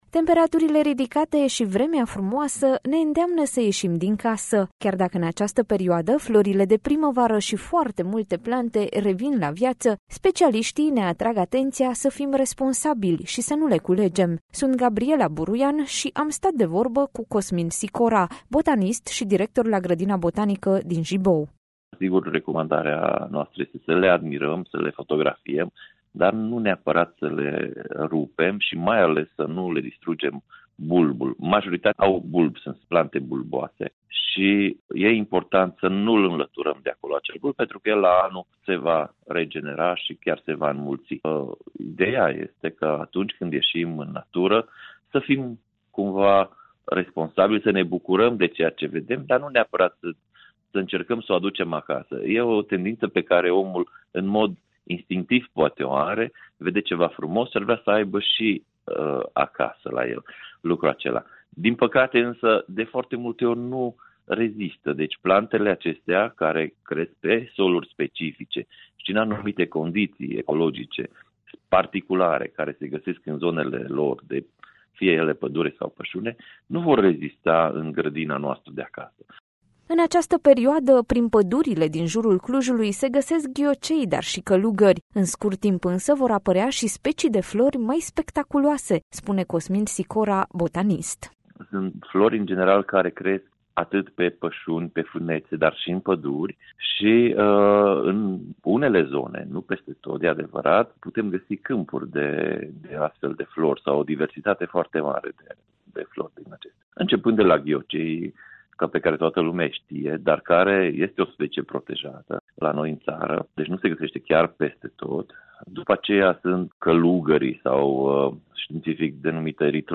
a stat de vorbă cu